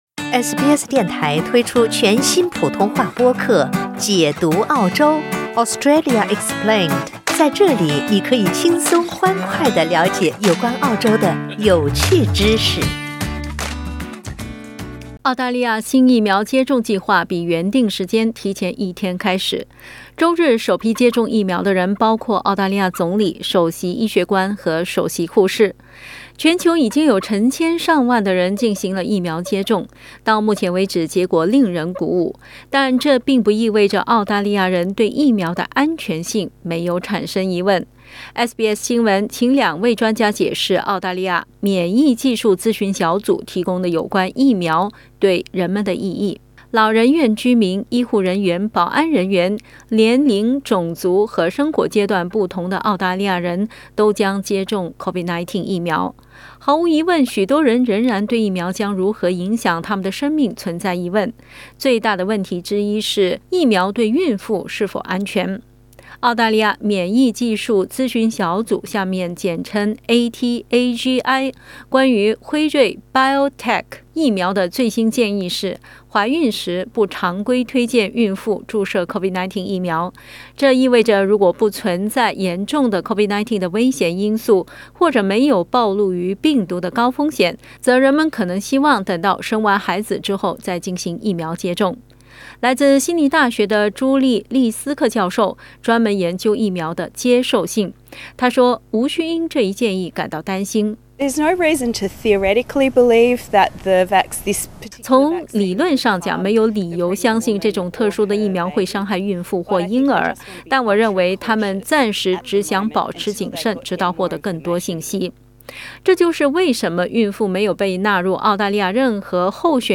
全球已有成千上萬人進行了疫苗接種，到目前為止，結果令人鼓舞，但這併不意味著澳大利亞人對疫苗的安全性沒有疑問。 SBS新聞請兩位專家解釋澳大利亞免疫技術諮詢小組（ATAGI ）提供的有關接種疫苗的建議。